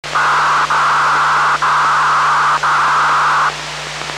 UnidSmartMeter.mp3